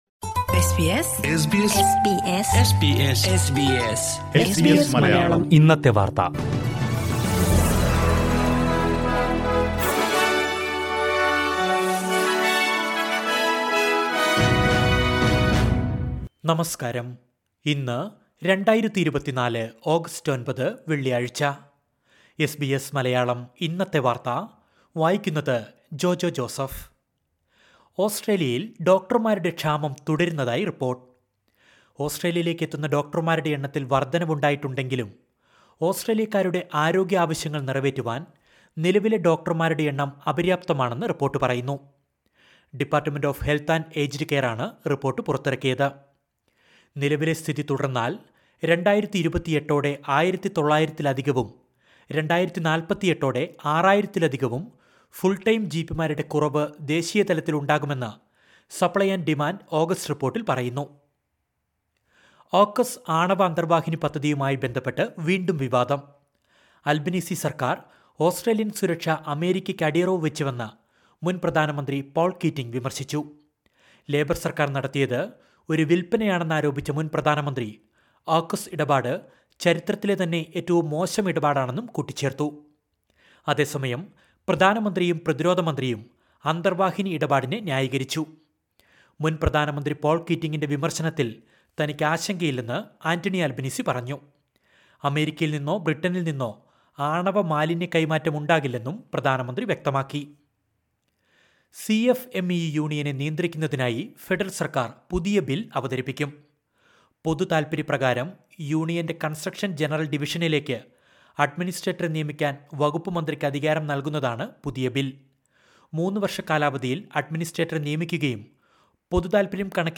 2024 ഓഗസ്റ്റ് ഒൻപതിലെ ഓസ്‌ട്രേലിയയിലെ ഏറ്റവും പ്രധാന വാര്‍ത്തകള്‍ കേള്‍ക്കാം...